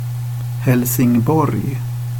Helsingborg (/ˈhɛlsɪŋbɔːrɡ/, US also /-bɔːr(jə), -bɔːri, ˌhɛlsɪŋˈbɔːri/,[2][3][4] Swedish: [hɛlsɪŋˈbɔrj]
Sv-Helsingborg.ogg.mp3